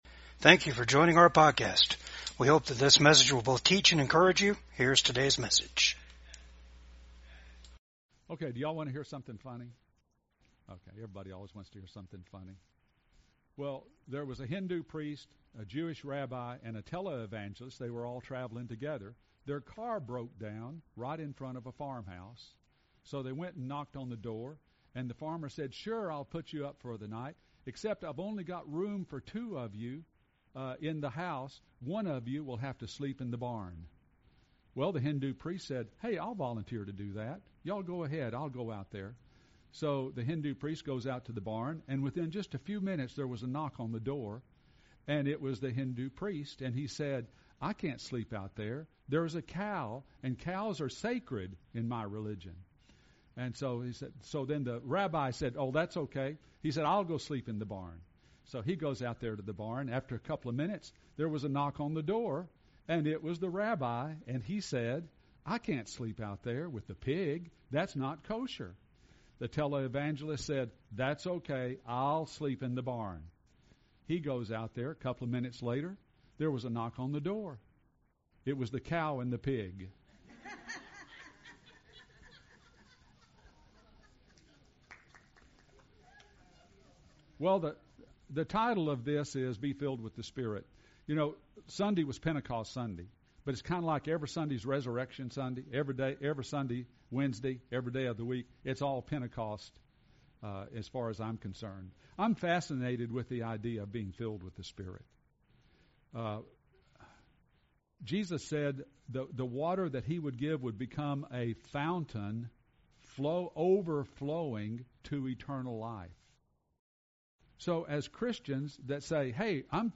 Passage: Ephesians 5:18-21 Service Type: VCAG WEDNESDAY SERVICE